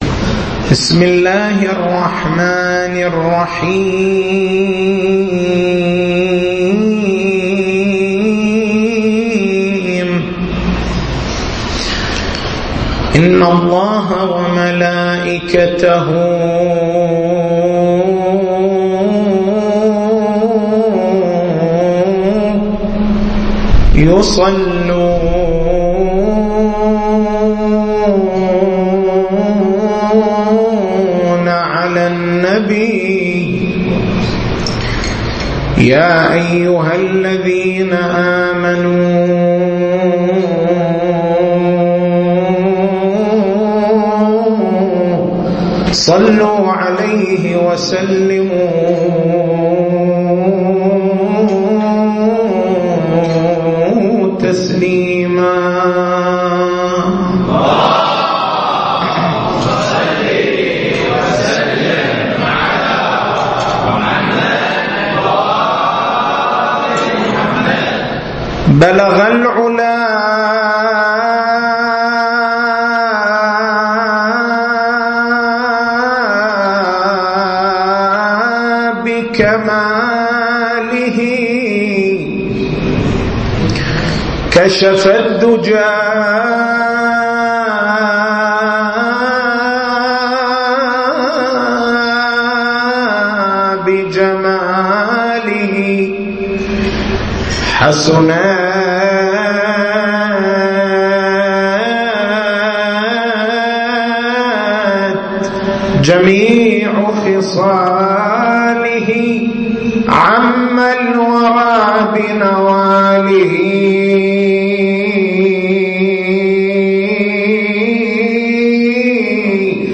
تاريخ المحاضرة: 15/09/1433